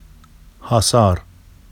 [ hɑsɑr ]
hɑsɑr.wav